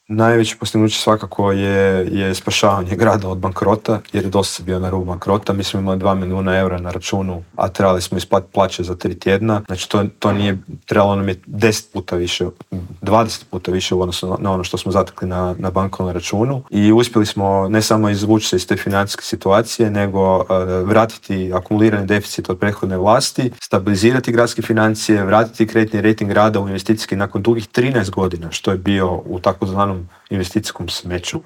ZAGREB - Blokovsko parkiranje, nikad više automobila u metropoli - najavljena je izgradnja nove infrastrukture - gradit će se Jarunski most, proširiti tramvajska mreža na Sarajevskoj cesti - što nas sve čeka u idućim godinama u metropoli u Intervjuu tjedna Media servisa rekao nam je zagrebački gradonačelnik Tomislav Tomašević - otkrio je i kada možemo očekivati završetak novog maksimirskog stadiona.